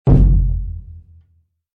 Surdo-7.mp3